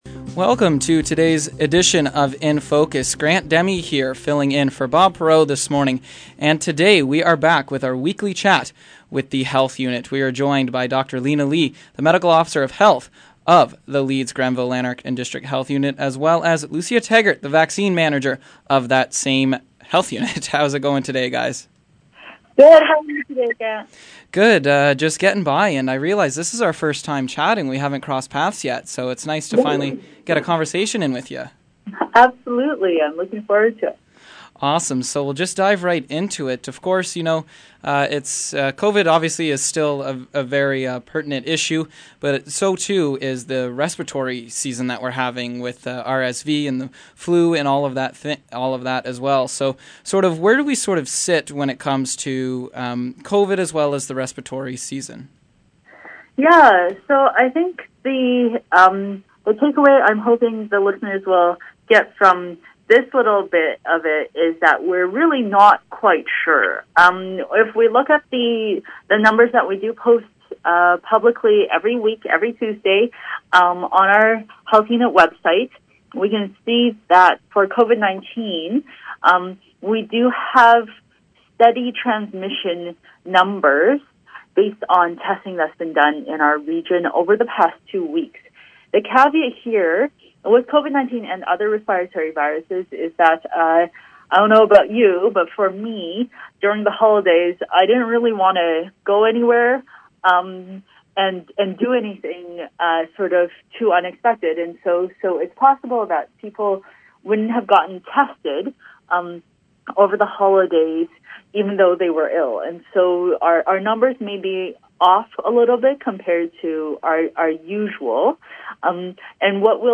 We are back live! We have another important update from the Leeds, Grenville and Lanark District Medical Officer of Health Dr. Linna Li.